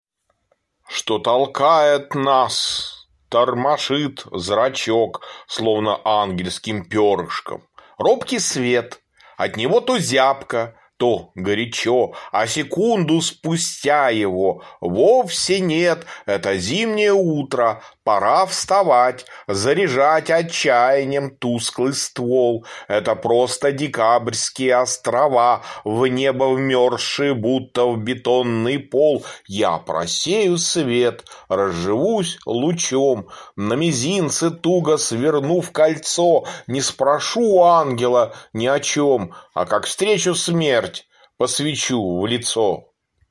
читает стихотворение «Что толкает нас, тормошит зрачок…»